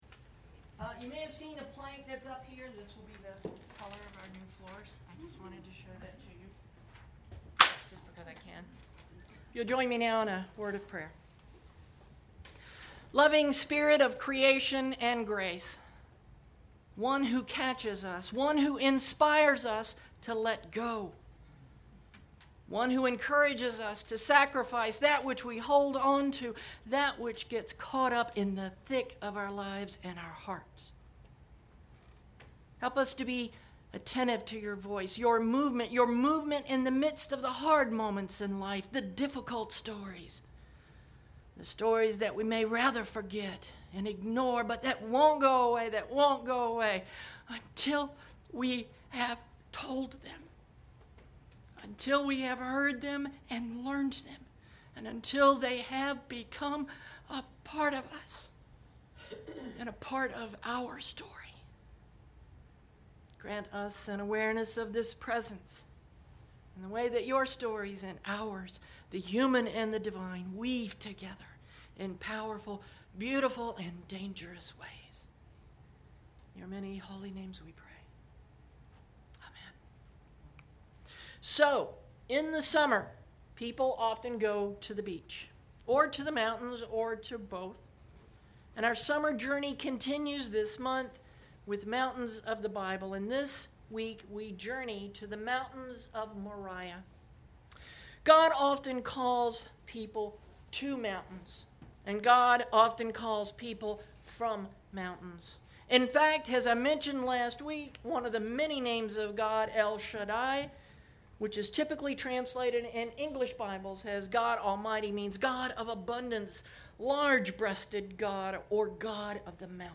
Sermon Posted